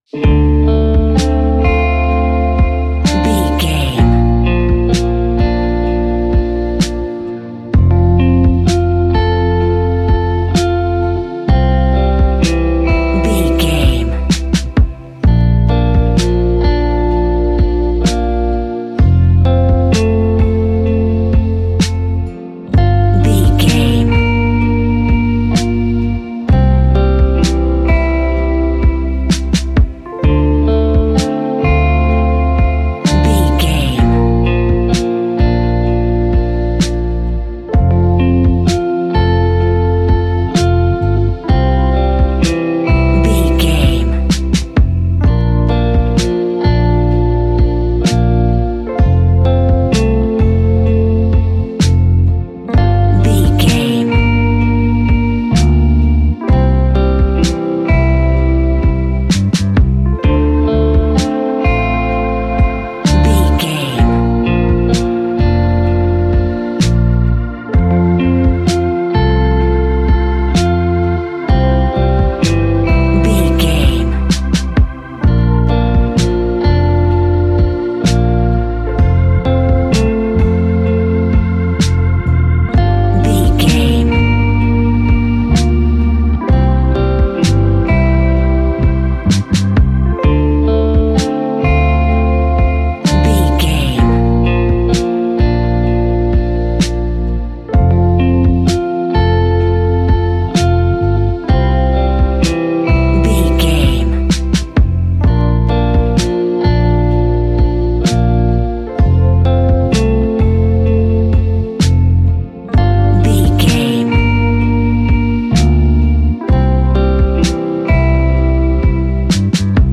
Ionian/Major
laid back
Lounge
sparse
chilled electronica
ambient
atmospheric